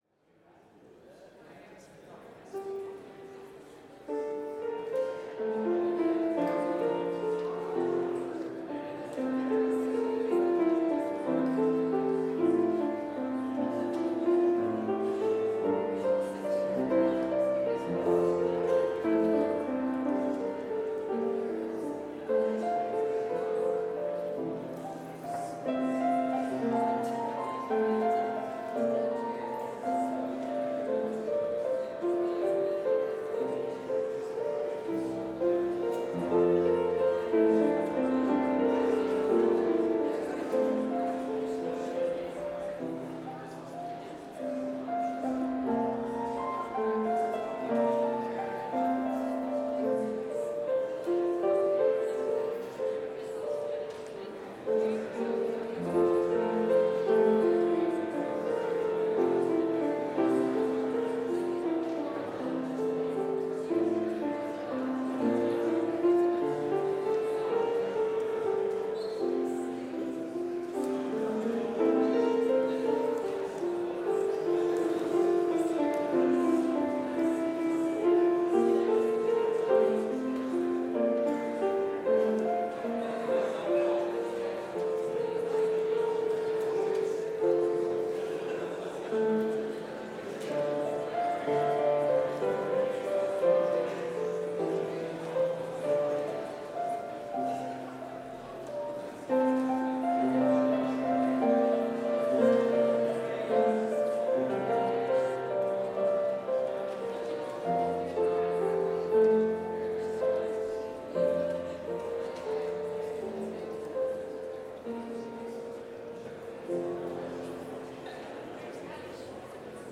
Complete service audio for Chapel - Thursday, February 22, 2024